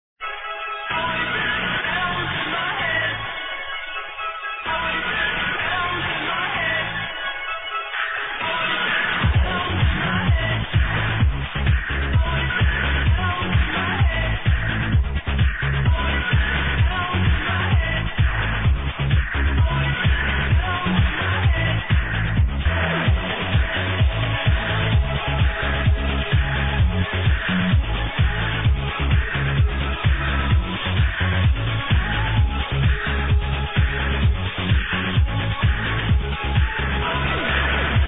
Thumbs up Good housy Tune